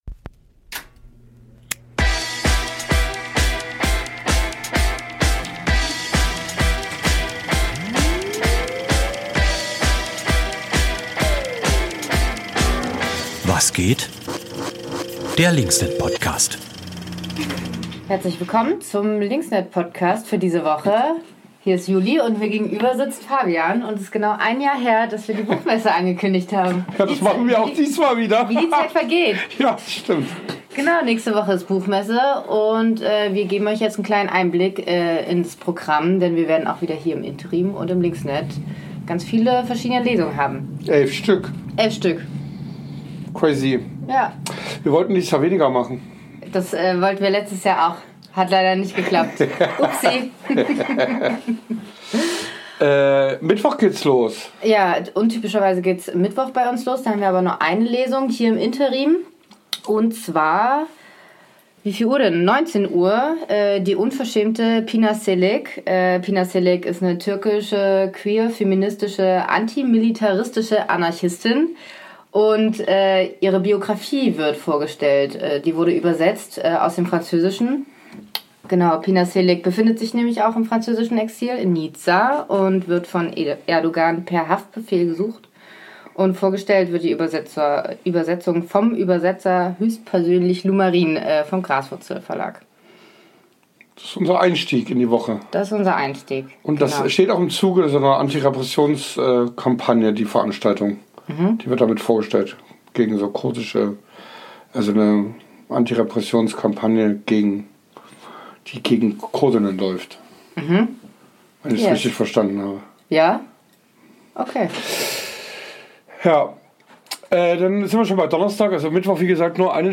stellen euch das Programm vor, lesen nicht nur den Flyer vor und stolpern über den ein oder anderen Zungenbrecher.